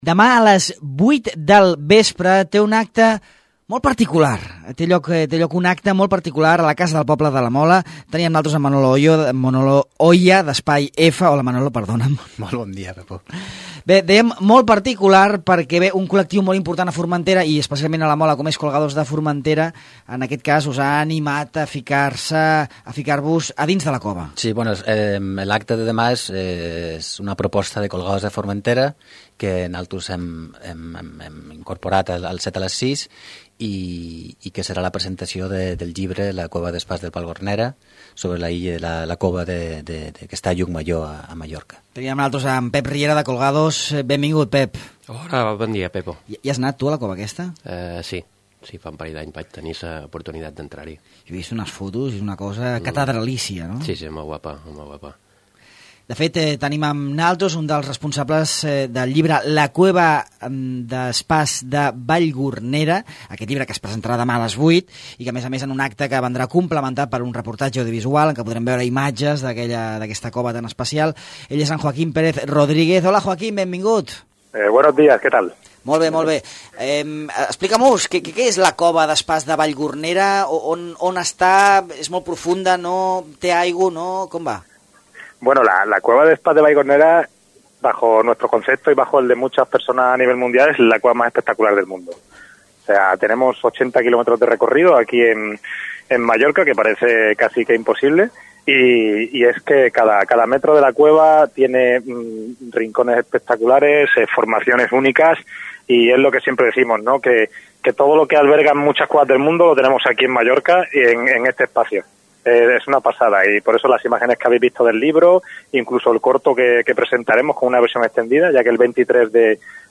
Recuperam una entrevista del passat mes d’abril sobre la presentació a la Casa del Poble de la Mola del llibre La cueva des pas de Vallgornera, una de les grutes més importants d’Europa, situada a Llucmajor.